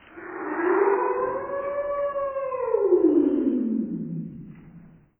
Wolf Howling Sound Effect Free Download
Wolf Howling